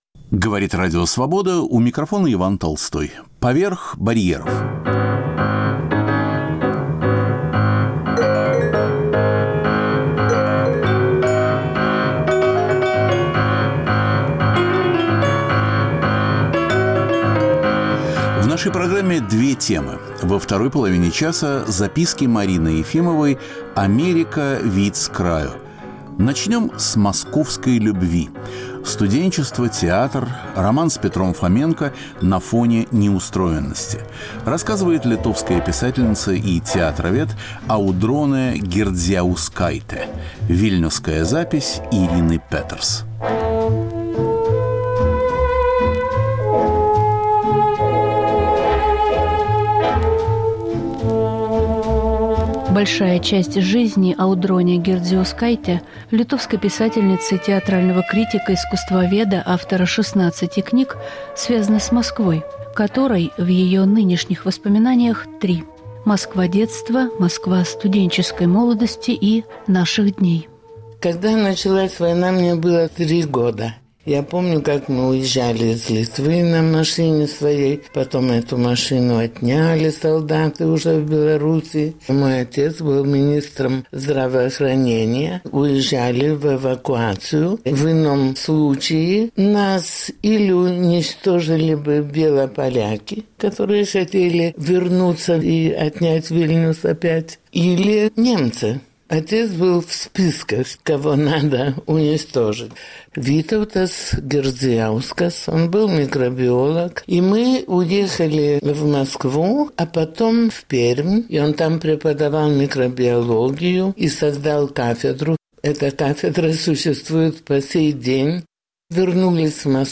Московская любовь: студенчество, театр, роман с режиссером Петром Фоменко. Рассказывает литовская писательница и театровед